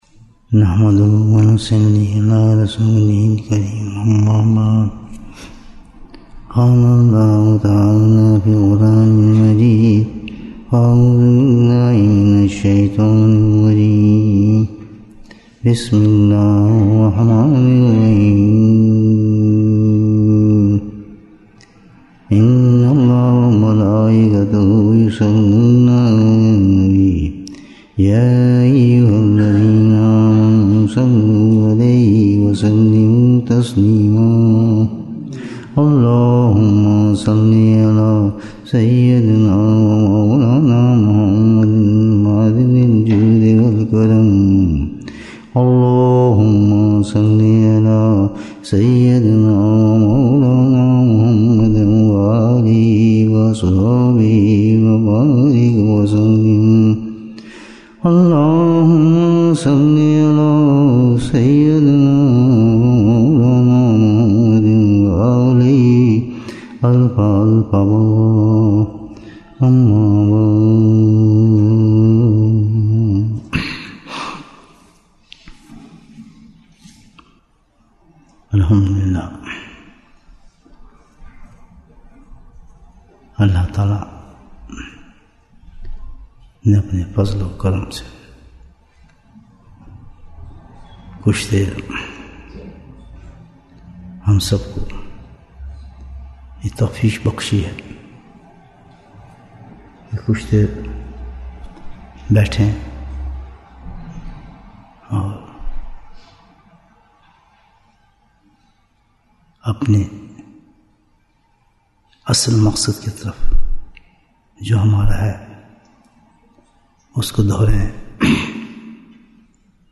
Bayan, 61 minutes 8th August, 2024 Click for English Download Audio Comments Hi there, We run a YouTube growth service, which increases your number of subscribers both safely and practically.